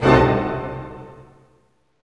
HIT ORCHDI02.wav